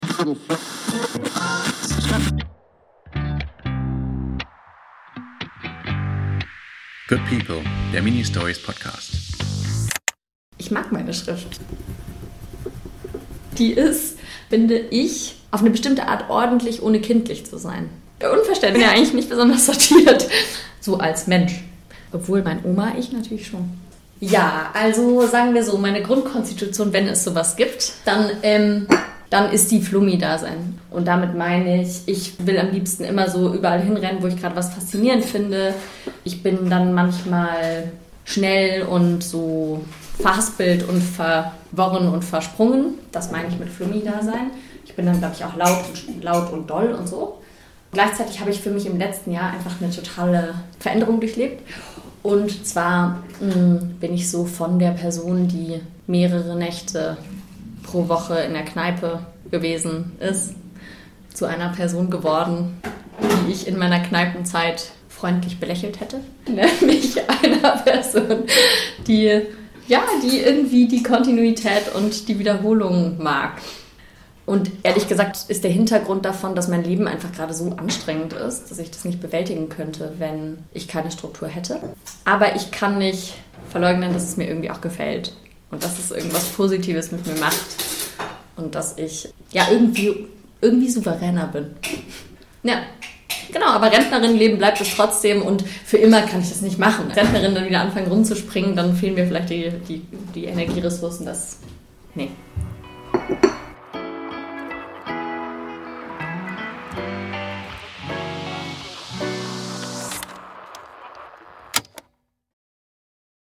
Wir erzählen kurze und kürzeste Geschichten über Menschen, die einander helfen, unterstützen, vertrauen, die etwas wagen, die trösten und lieben, die uns überraschen, ihre Schmerzen und ihr Glück zeigen, die uns unerwartet entgegenkommen, die uns retten und ermutigen, die zuhören, ihr Wissen teilen, freundlich herüberschauen, die freiwillig etwas tun, die unbequem sind und damit Freiräume schaffen, die sich nicht so wichtig nehmen, die sich erinnern, die hoffen und träumen, die alt sind und für die Jungen da sind, die noch klein sind und für die Alten da sind, die uns beschützen, die mit uns springen und die mit uns sprechen. In schneller Folge entstehen Mini-Portraits von vielen verschiedenen Menschen.